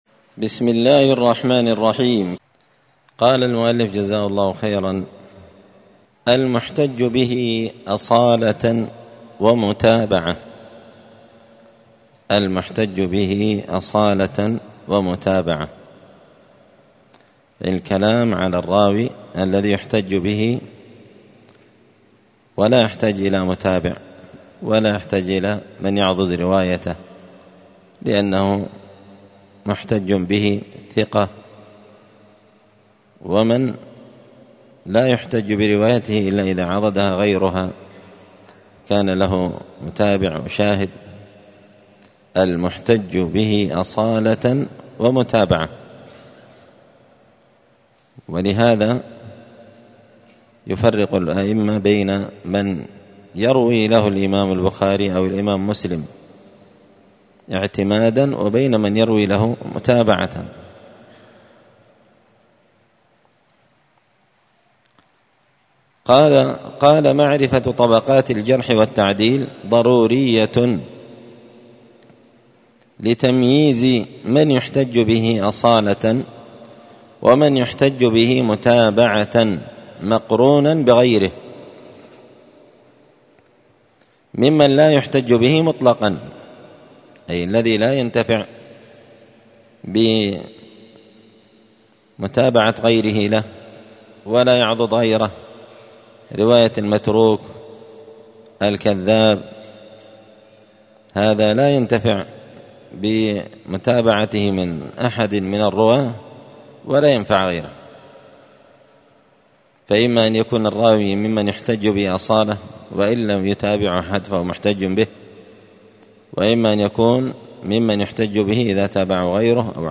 *الدرس الثاني والعشرون (22) المحتج به أصالة ومتابعة*